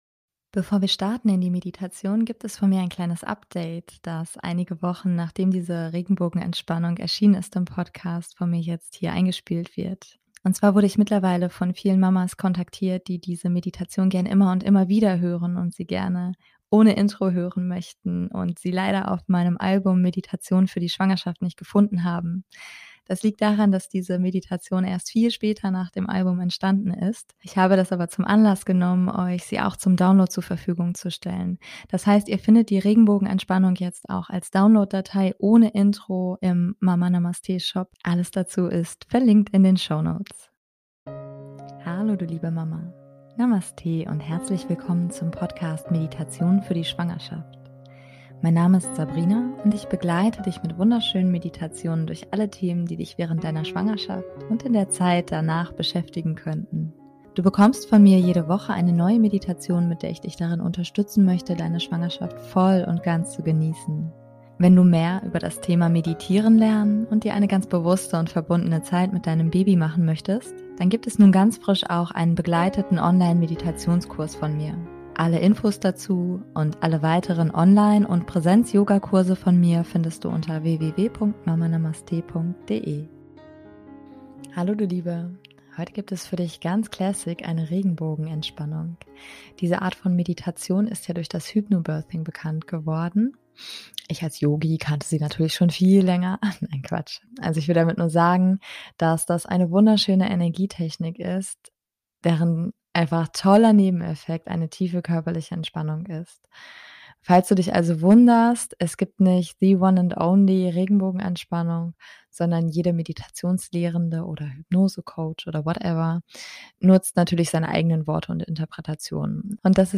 #096 - Regenbogenentspannung [für Schwangerschaft & Geburt] ~ Meditationen für die Schwangerschaft und Geburt - mama.namaste Podcast
Heute gibt es für dich ganz classic eine Regenbogenentspannung.